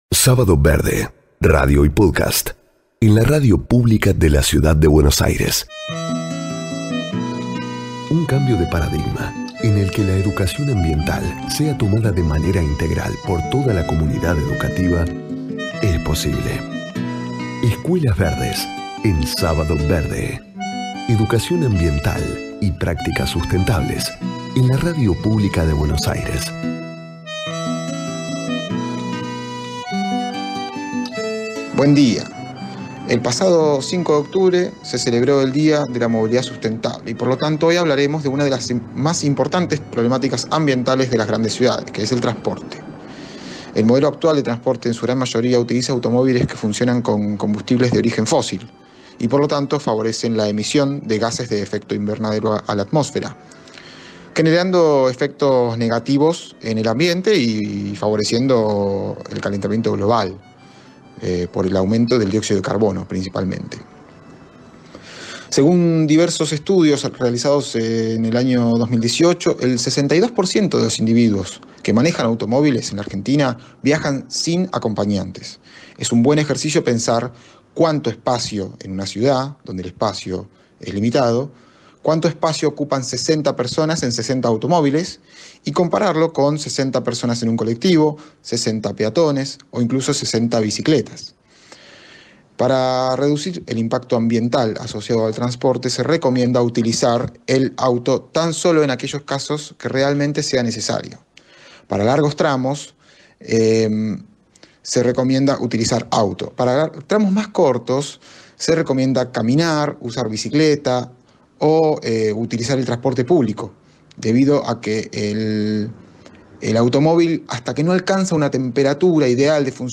Profundizá en diversas temáticas ambientales, en palabra de los/as especialistas de Escuelas Verdes y Ciudadanía Global.